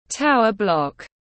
Tòa nhà cao tầng tiếng anh gọi là tower block, phiên âm tiếng anh đọc là /ˈtaʊə ˌblɒk/.
Tower block /ˈtaʊə ˌblɒk/